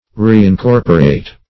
Reincorporate \Re`in*cor"po*rate\